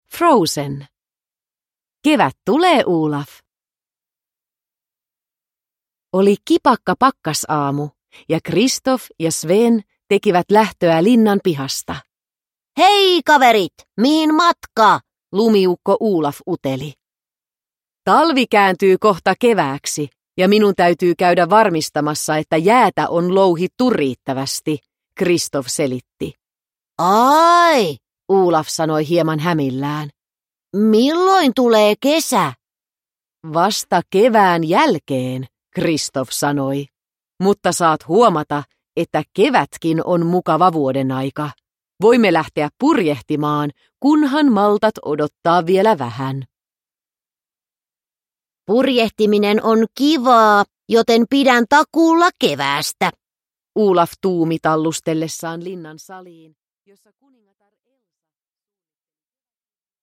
Kevät tulee, Olaf! – Ljudbok – Laddas ner